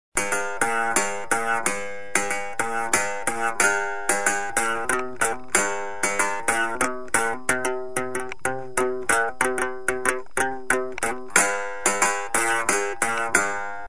URM Sonos de Sardigna : strumenti muicali antichi - Raschiamento in zucca
Serraggia.mp3